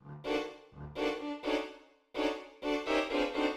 Changing metre
This practice is sometimes called mixed metres.